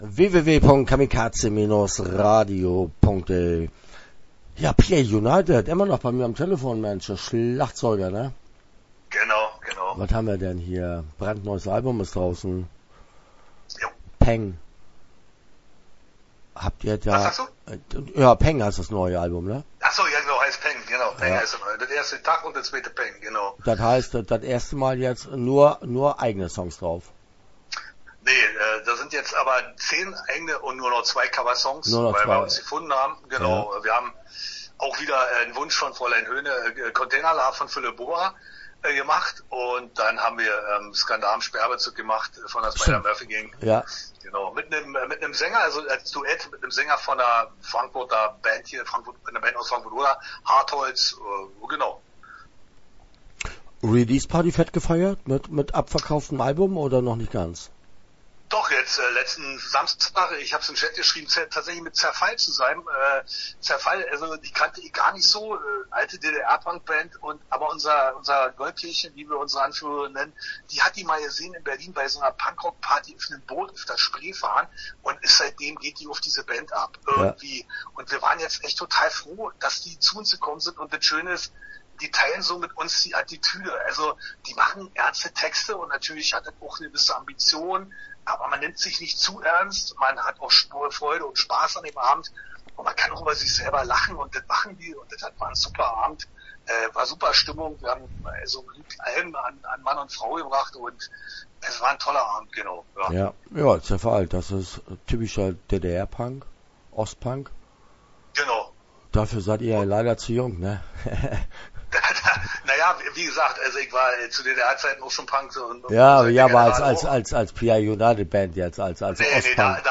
Start » Interviews » Pia United